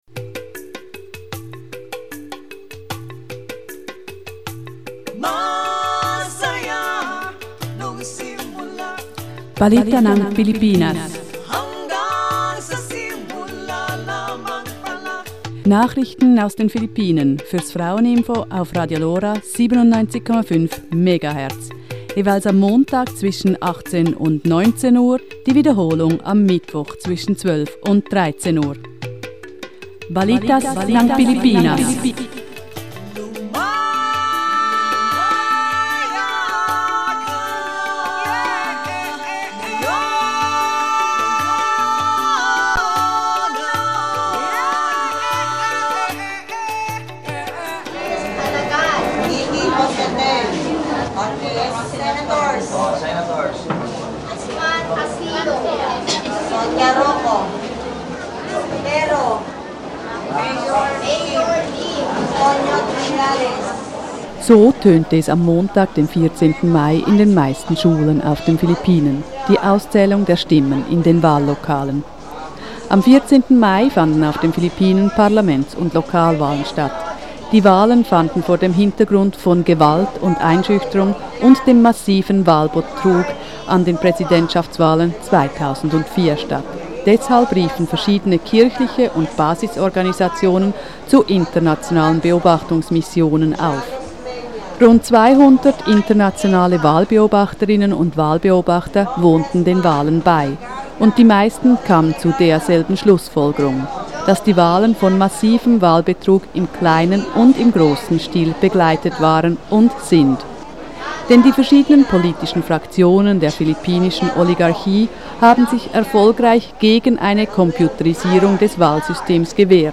Ein Radiobericht über die Internationale Beobachtungsmission an den Wahlen mit Zeugenaussagen und Einschätzungen der diesjährigen Wahlen, die vor dem Hintergrund des Wahlbetrug 2004, der Gewalt und Einschüchterung, der Politischen Morden und der Straflosigkeit gesehen werden müssen.